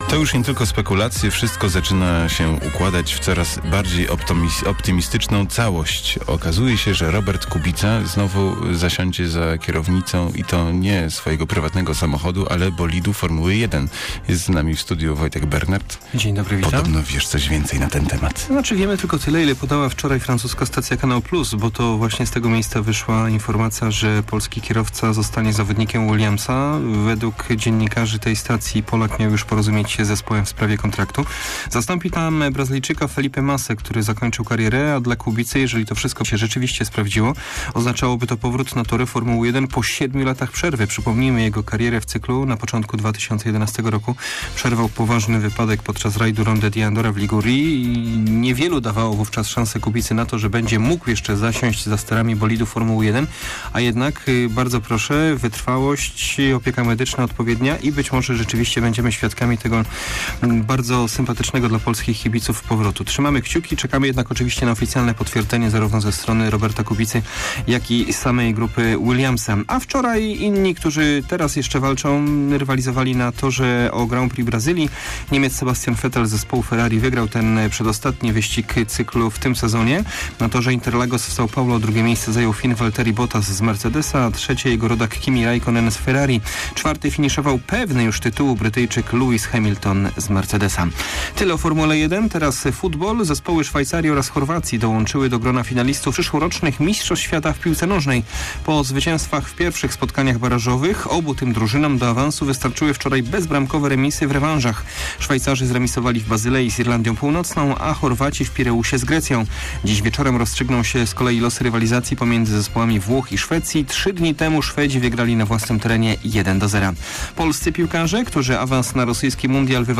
13.11 serwis sportowy godz. 7:45